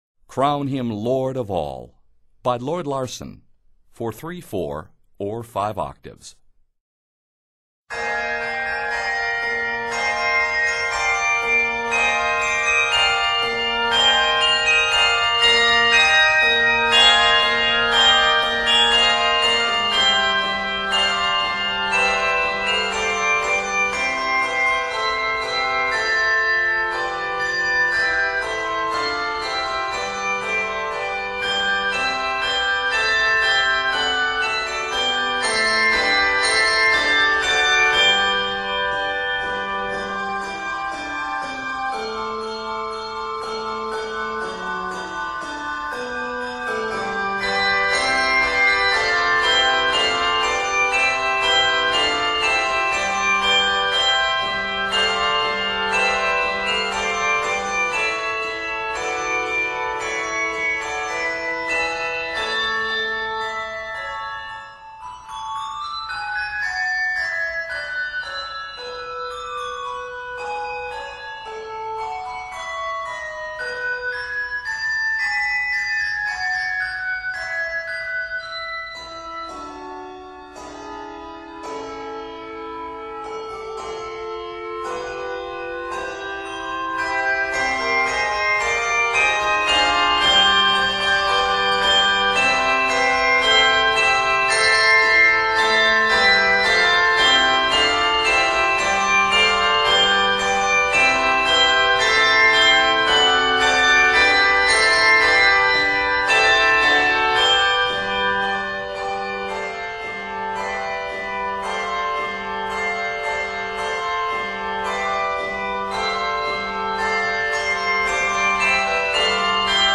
set in C Major, A Major, and F Major